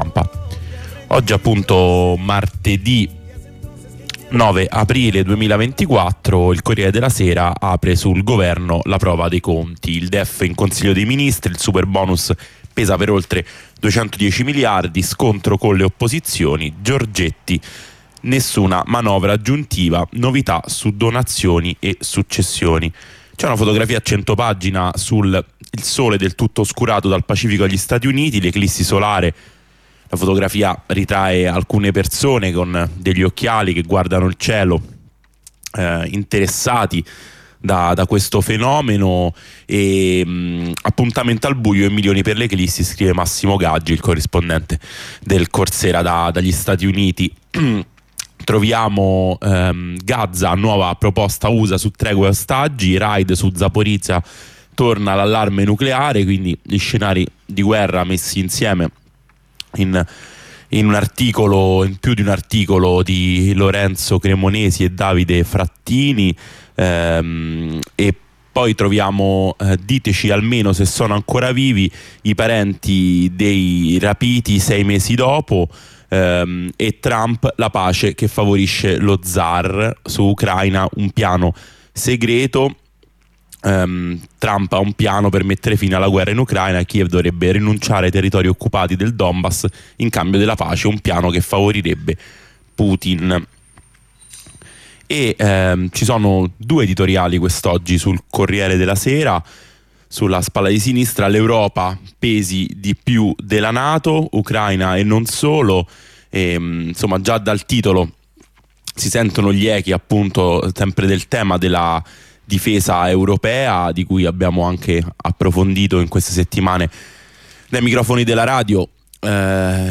Lettura e commento dei quotidiani, ogni mattina, da lunedì a venerdì alle 8, il sabato alle 9.